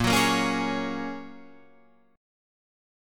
Bbm7#5 chord